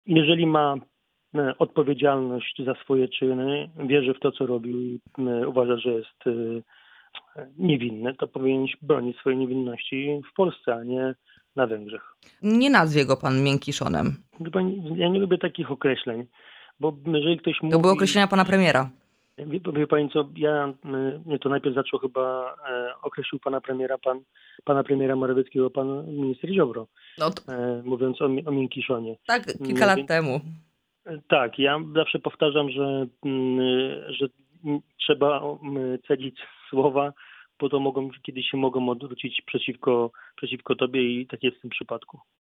Michał Jaros – wiceminister rozwoju i technologii, szef dolnośląskich struktur KO był dziś naszym „Porannym Gościem”.